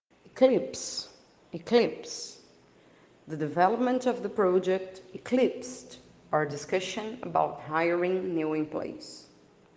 Attention to pronunciation: